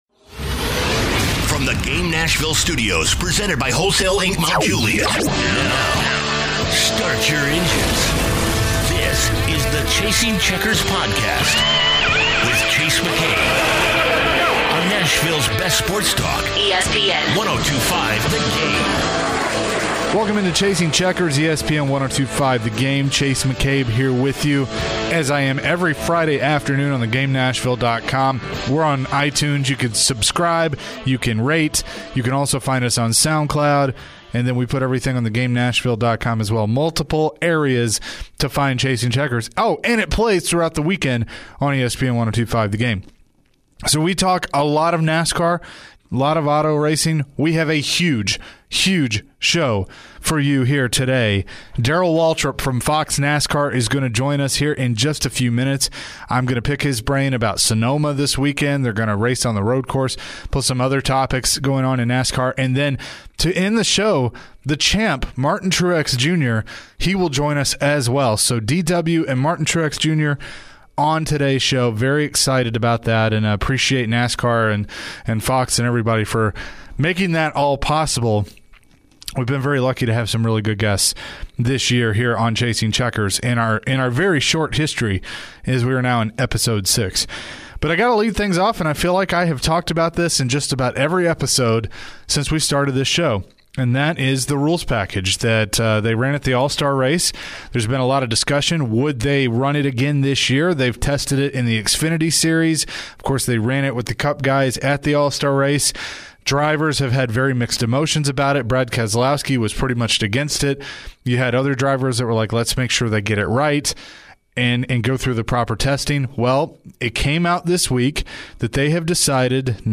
This week the drivers of NASCAR's Cup series turn right! Also, he's joined by Fox NASCAR Analyst, Darrell Waltrip and defending Cup series champion, Martin Truex Jr.